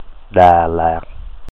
Da Lat, or Dalat[1] (Vietnamese: Đà Lạt; Vietnamese pronunciation: [ɗâː làːt̚]